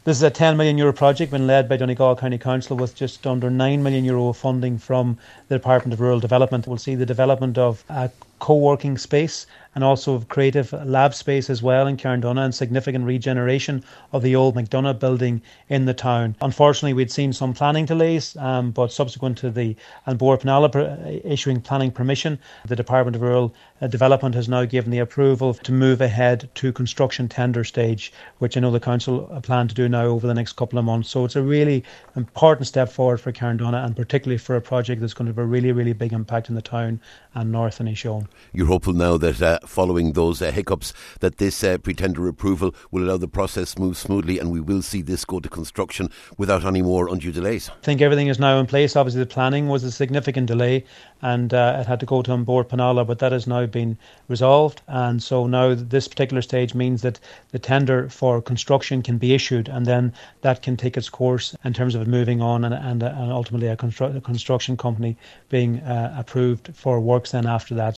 Minister Charlie McConalogue is hopeful we’ll see significant progress in the coming months……….